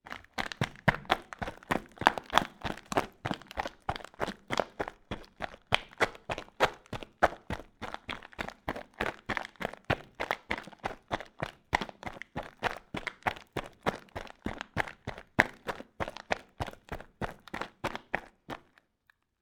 Footsteps
Gravel_Ice_Shoes_Running.wav